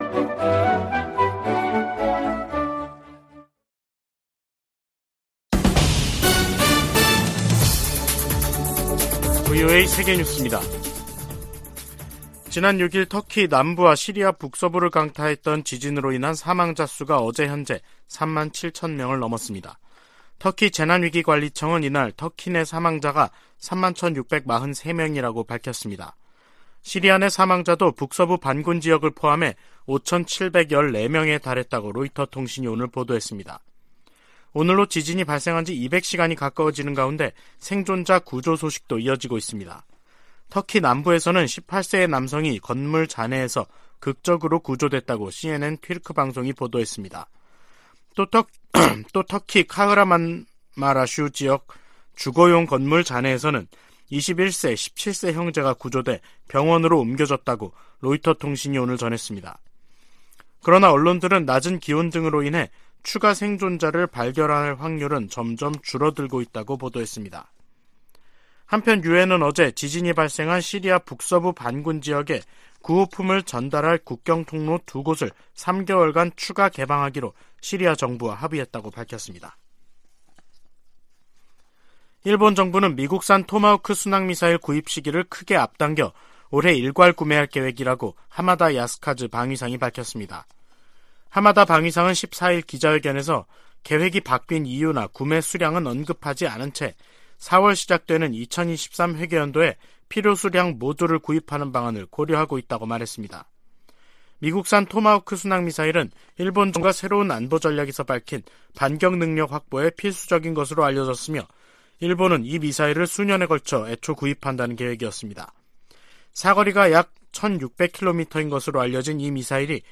VOA 한국어 간판 뉴스 프로그램 '뉴스 투데이', 2023년 2월 14일 3부 방송입니다. 미국과 한국, 일본의 외교 차관들이 워싱턴에서 회의를 열고 북한의 핵과 미사일 위협에 대응해 삼각 공조를 강화하기로 했습니다. 미국 정부는 중국 등에 유엔 안보리 대북 결의의 문구와 정신을 따라야 한다고 촉구했습니다. 백악관은 중국의 정찰풍선이 전 세계 수십 개 국가를 통과했다는 사실을 거듭 확인했습니다.